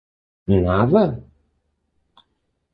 [ˈnaðɐ]